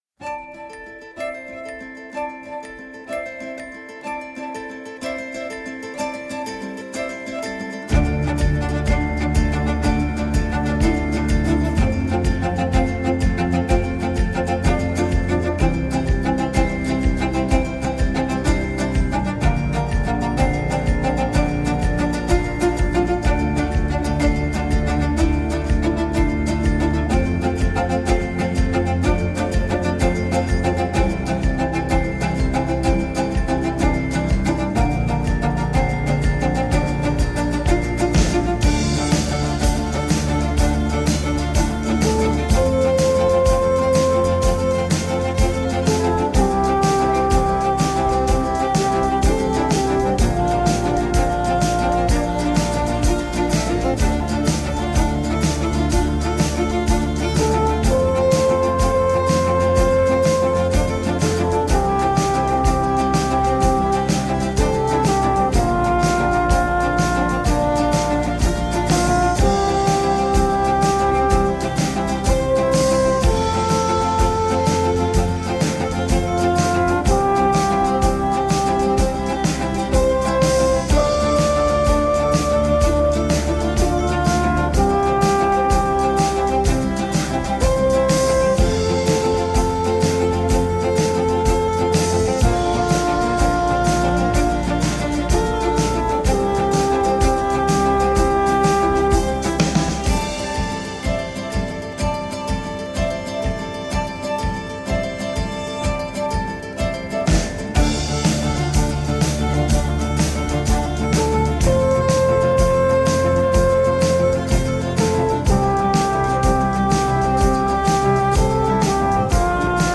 Early music meets global folk at the penguin café.
Tagged as: World, Folk-Rock